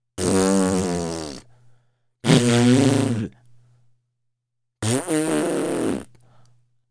03311 cartoon mocking tongue
blow-a-raspberry bronx-cheer cartoon doctor fart kid mock raspberry sound effect free sound royalty free Movies & TV